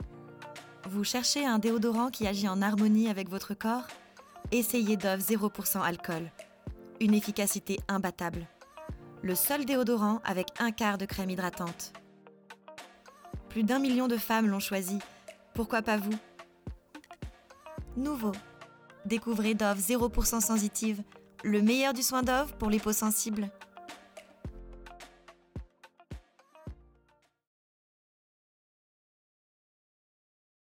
Extrait Bande Voix
Voix off